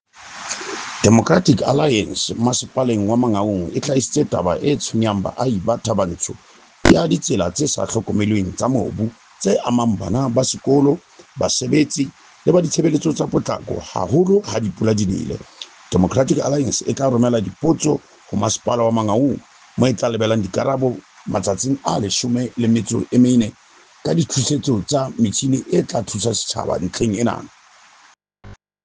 Sesotho soundbites by Cllr Tumelo Rammile and